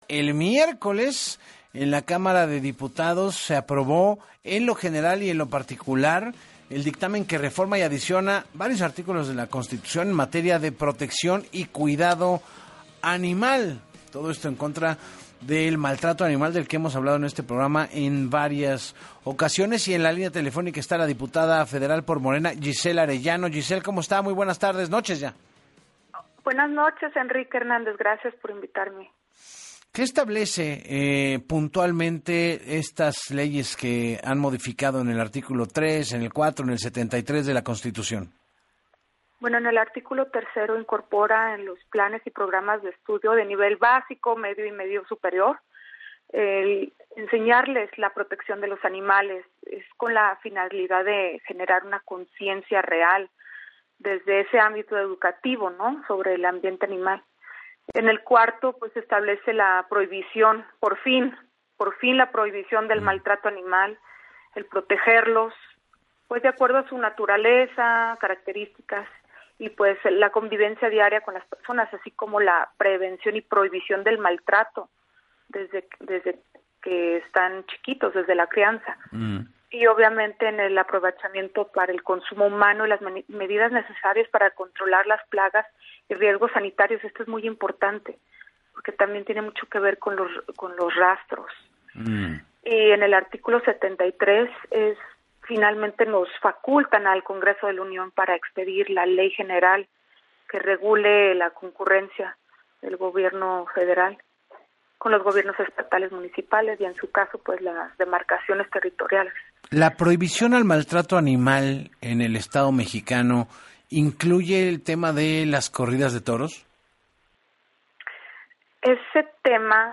En entrevista para Así Las Cosas con Enrique Hernández Alcázar, la diputada federal de Morena, Giselle Arellano, destacó que en México no existe una ley a nivel federal que proteja a los animales, pero que esta reforma es el primer paso para crear una ley federal de bienestar animal.